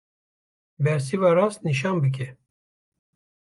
Pronounced as (IPA) /rɑːst/